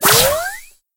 chester_stun_throw_01.ogg